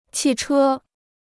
汽车 (qì chē): car; automobile.